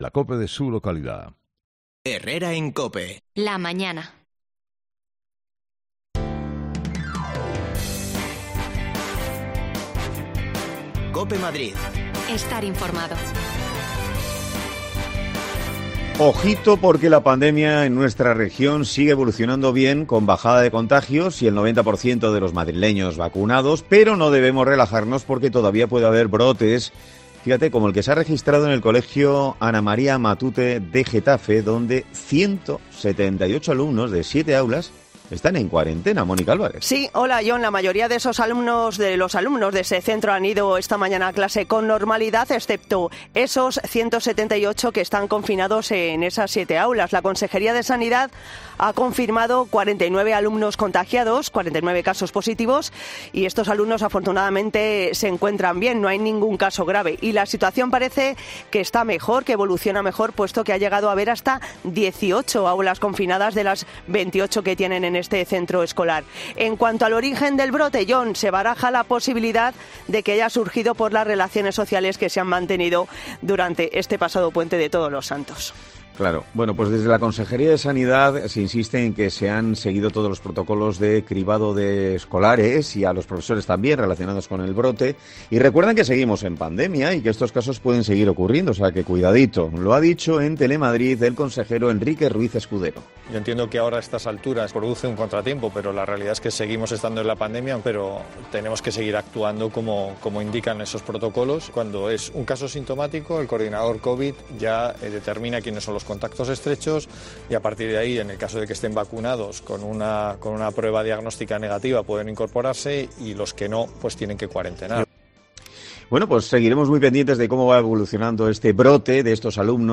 Escucha ya las desconexiones locales de Madrid de Herrera en COPE en Madrid y Mediodía COPE en Madrid .
Las desconexiones locales de Madrid son espacios de 10 minutos de duración que se emiten en COPE , de lunes a viernes.